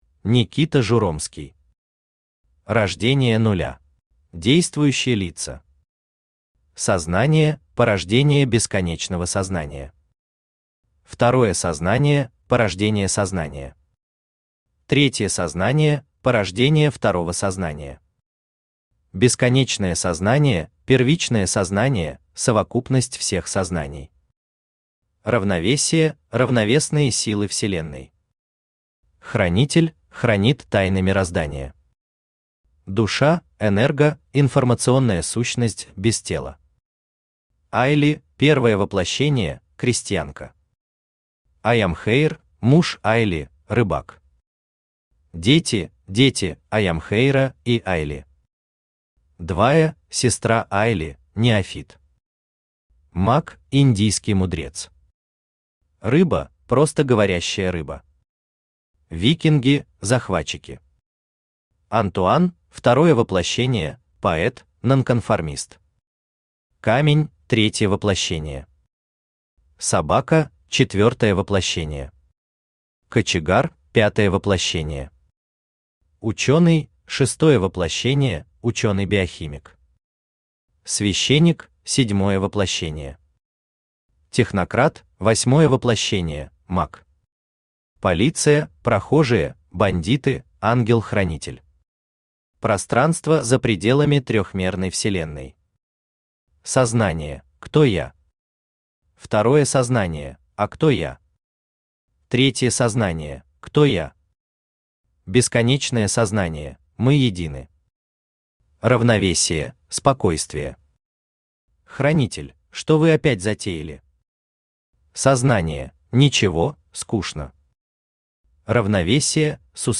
Аудиокнига Рождение нуля | Библиотека аудиокниг
Aудиокнига Рождение нуля Автор Никита Журомский Читает аудиокнигу Авточтец ЛитРес.